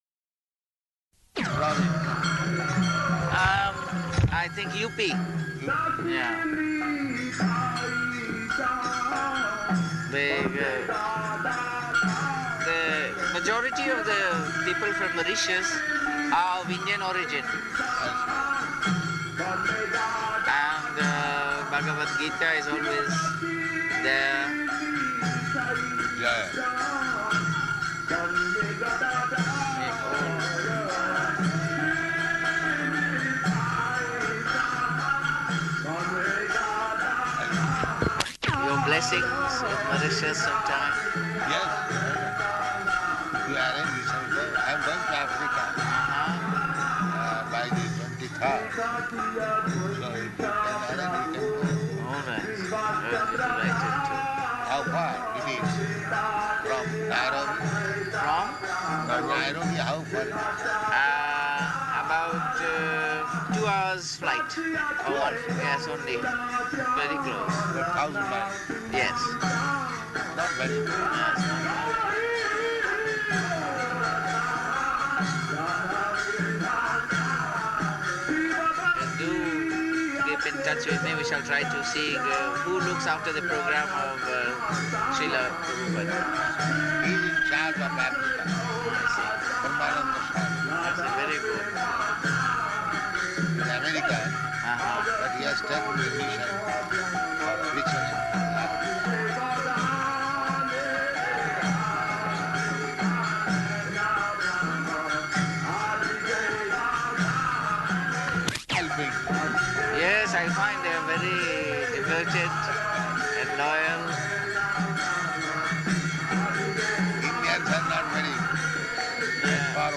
Conversation
Conversation --:-- --:-- Type: Conversation Dated: November 11th 1971 Location: Delhi Audio file: 711111R1-DELHI.mp3 [loud kīrtana in background] Mauritius man: Hmm.